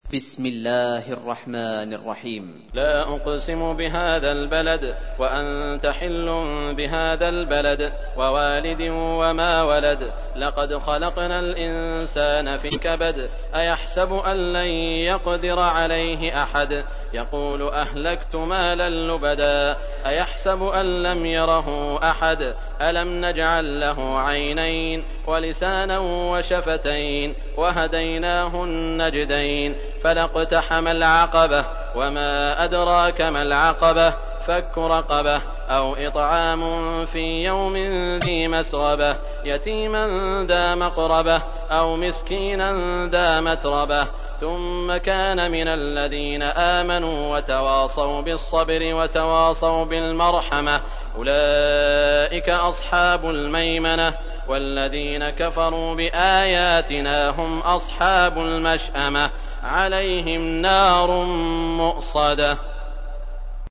تحميل : 90. سورة البلد / القارئ سعود الشريم / القرآن الكريم / موقع يا حسين